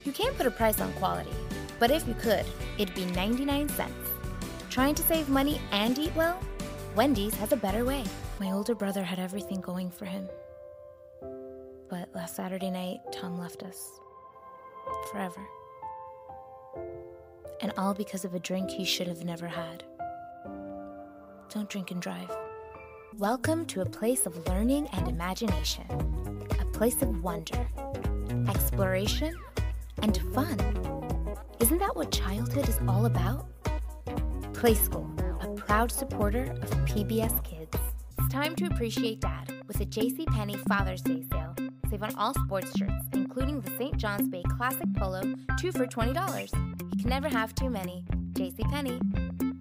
Spanish Commercial Demo